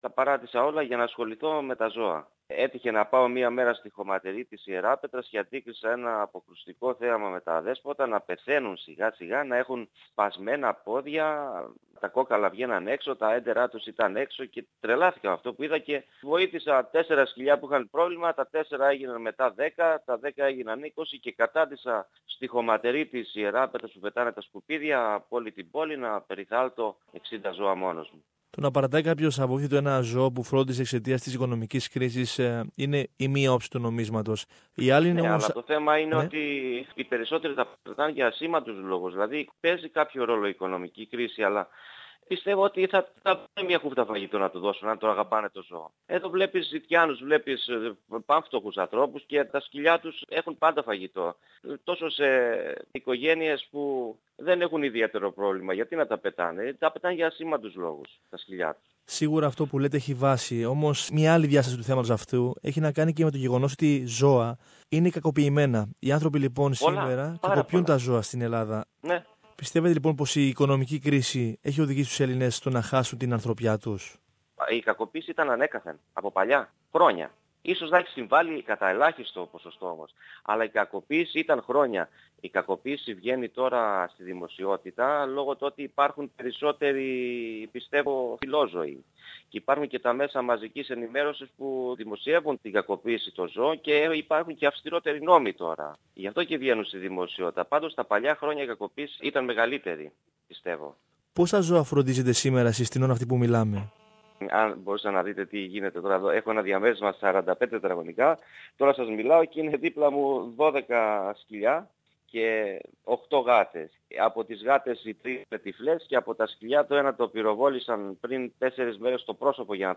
Περισσότερα ακούμε στην συνέντευξη